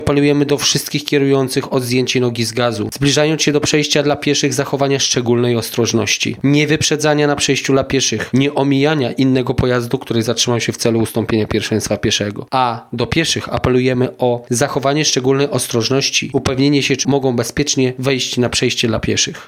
Wiadomości Radom